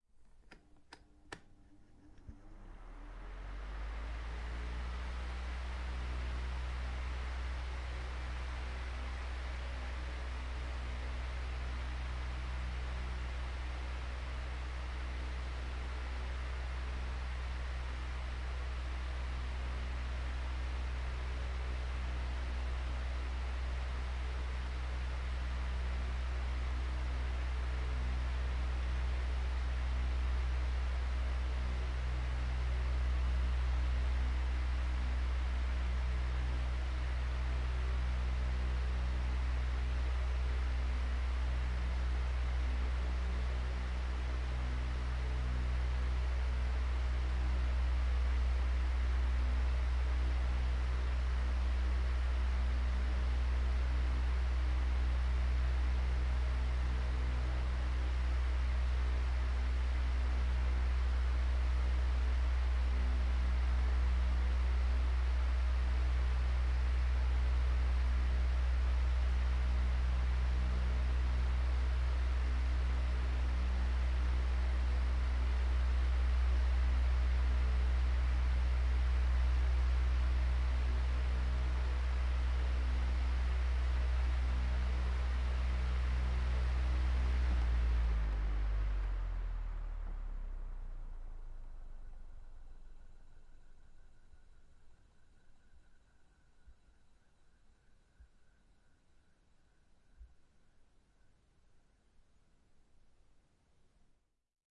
风扇吹动 " 风扇，设置3
描述：风扇吹，设置为最高设置。有趣的是，有一个强大的低端存在。录音是从风扇后面进行的，因此空气不会直接吹入麦克风。 用Zoom H1记录。
Tag: 空调 风扇 风扇 风机 空气 通风 吹制 AC 通风口 弗利